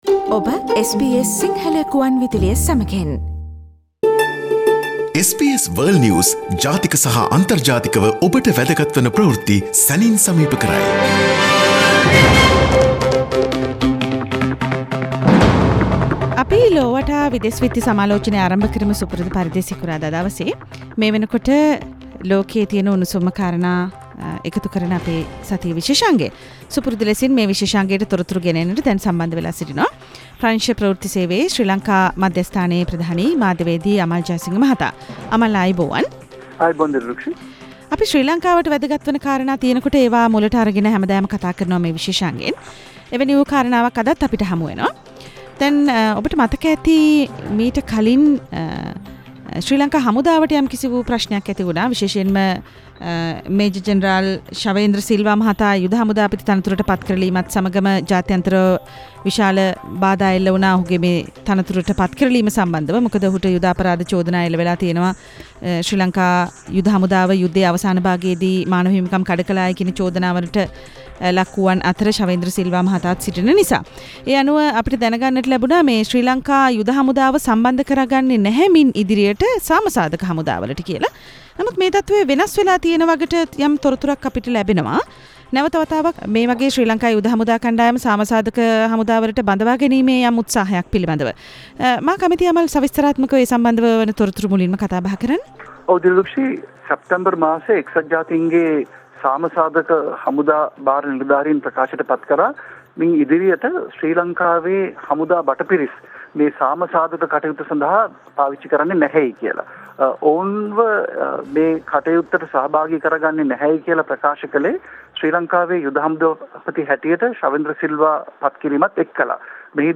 SBS Sinhala weekly world news wrap Source: SBS Sinhala